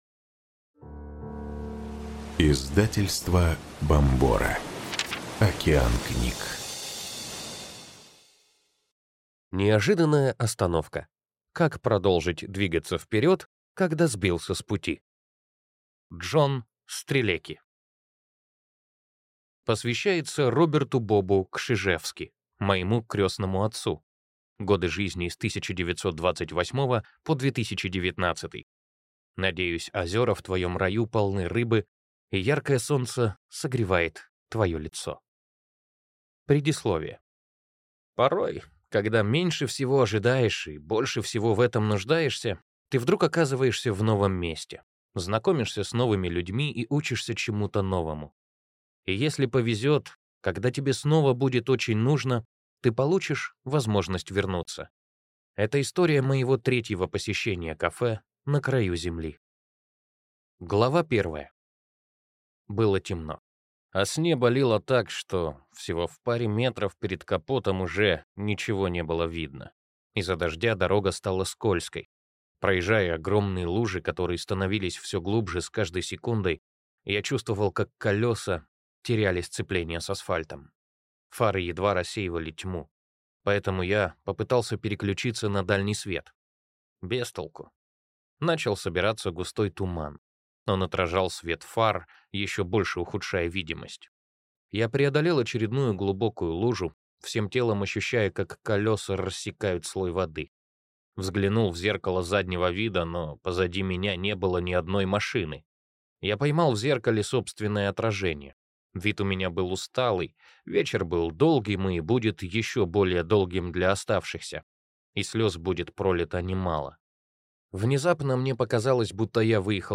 Аудиокнига Неожиданная остановка. Как продолжить двигаться вперед, когда сбился с пути | Библиотека аудиокниг